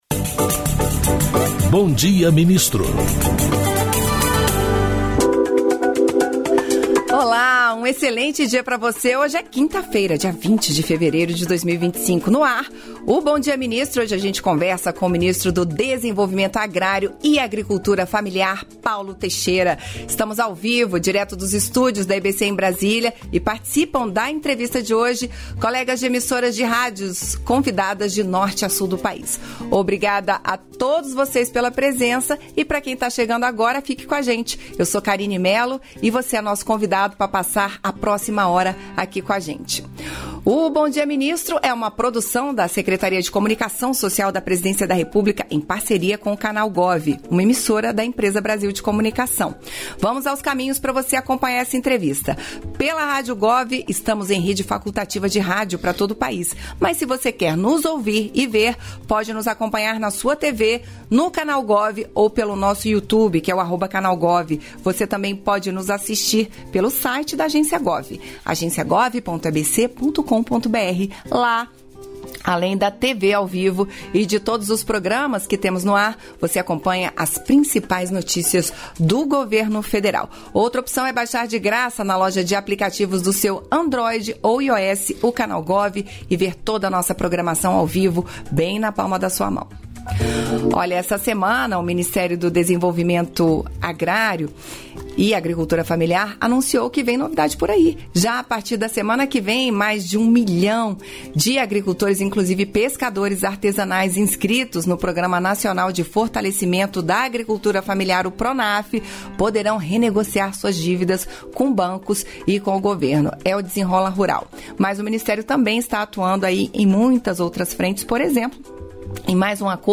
Íntegra da participação do ministro do Desenvolvimento Agrário e Agricultura Familiar, Paulo Teixeira, no programa "Bom Dia, Ministro" desta quinta-feira (20), nos estúdios da EBC, em Brasília.